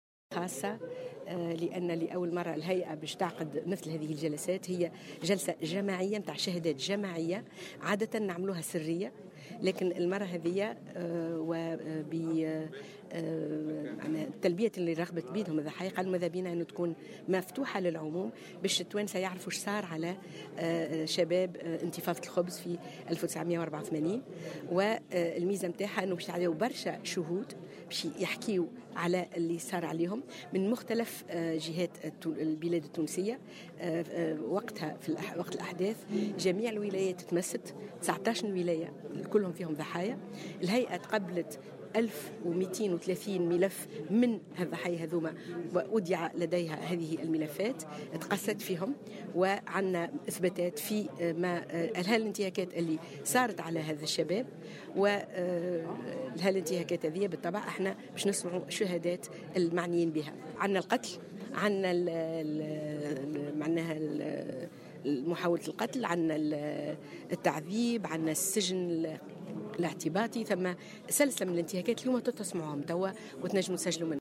Une séance d'audition publique sera tenue ce jeudi par l'Instance Vérité et Dignité (IVD) autour des victimes "des émeutes du pain", a affirmé Sihem Ben Sedrine, la présidente de l'instance, au micro du correspondant de Jawhara FM.